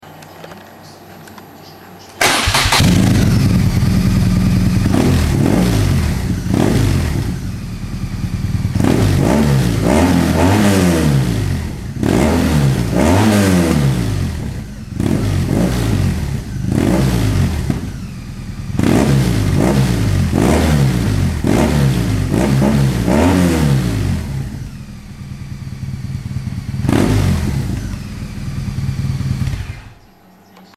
ZACH für Italien, Slowenien und Kroation ohne ALLES
Auspuff ohne DB Killer
auspuff_ohne_DB.mp3